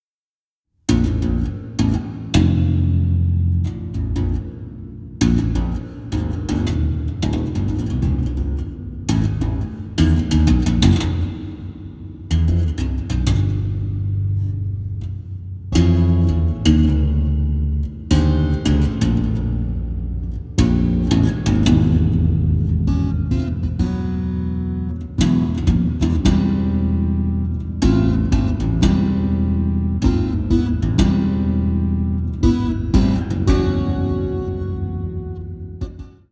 36-string Double Contraguitar, 30-string Contra-Alto guitar